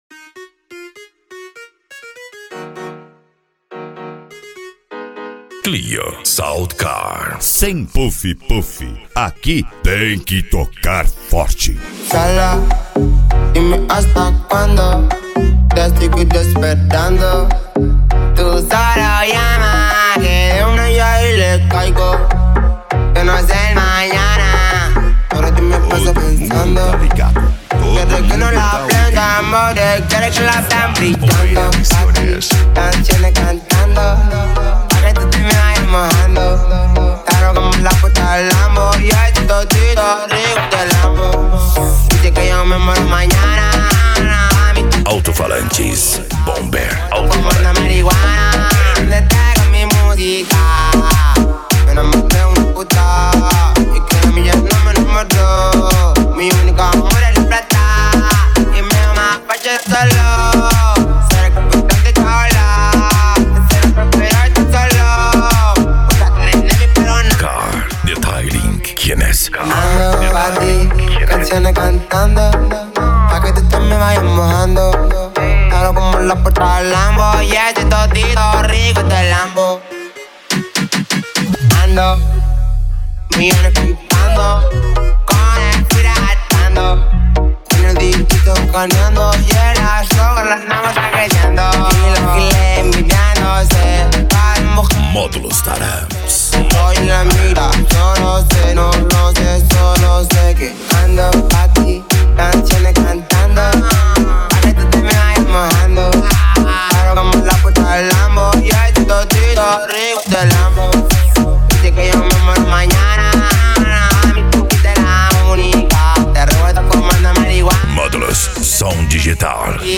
Bass
Eletronica
Modao
Remix